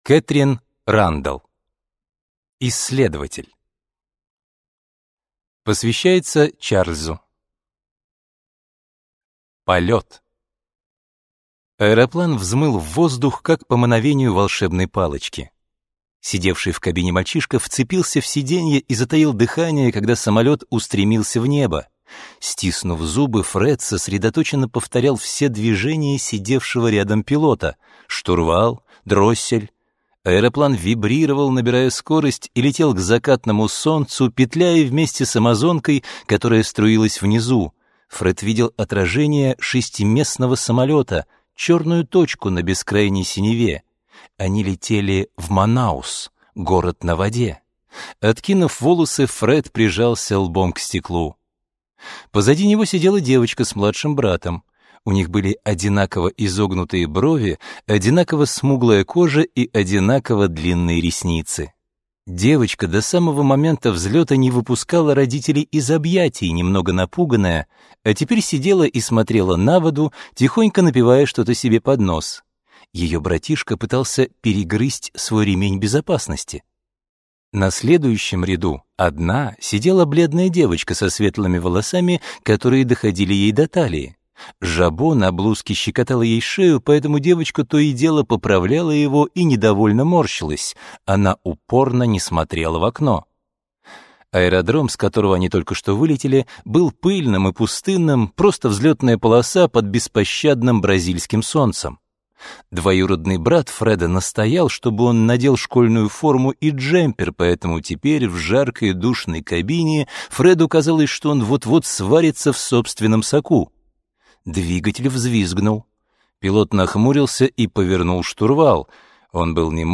Аудиокнига Исследователь | Библиотека аудиокниг